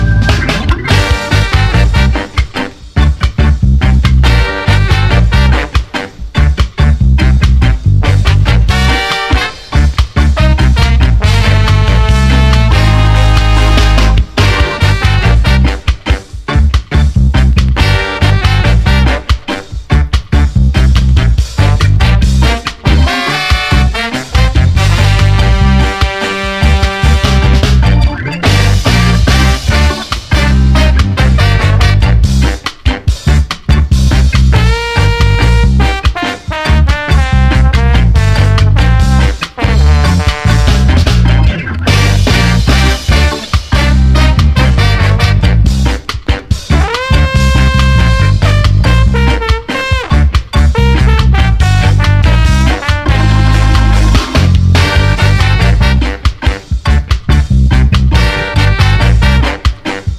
ファンキー・グルーヴ
小気味いいMOOGポップ